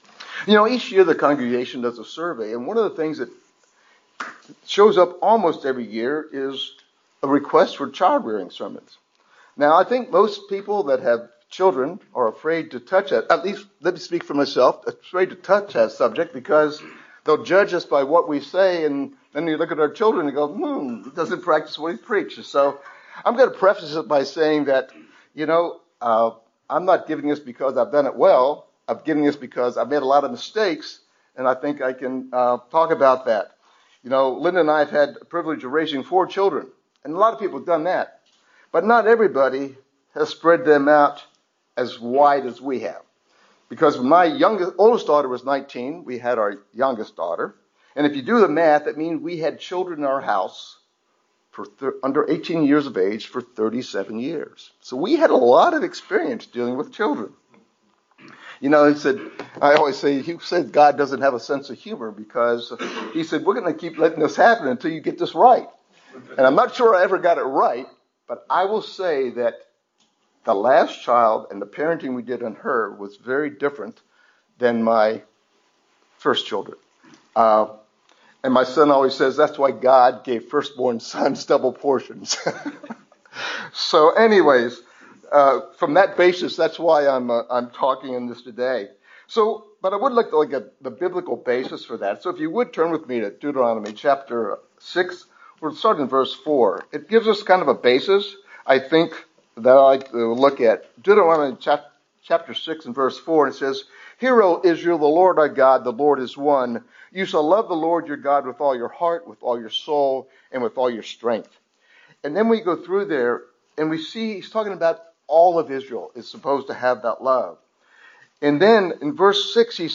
Sermons
Given in Buford, GA